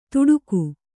♪ tuḍuku